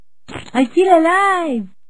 His accent is a little hard to decipher, so I don't see this ending well.
It sounds like "I feel alive" to me, but I think it's just his accent so I think "I still alive".